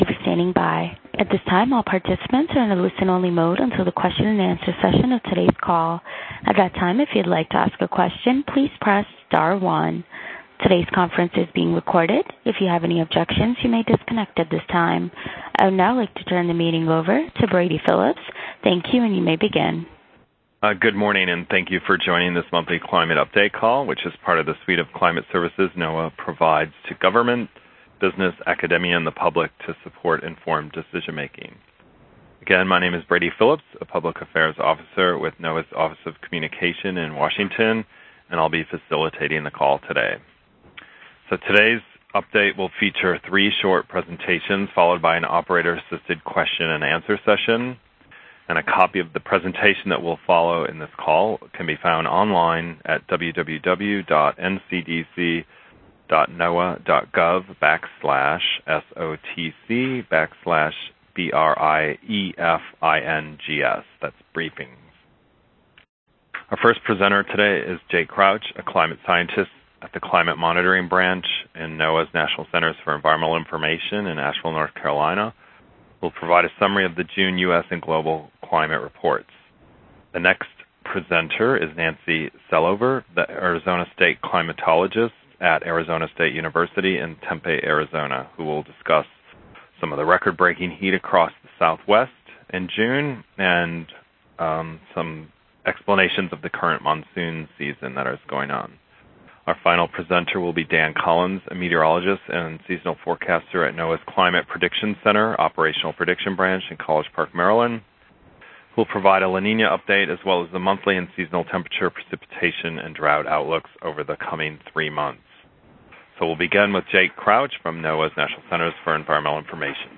Experts to hold media teleconference on climate conditions for U.S. and globe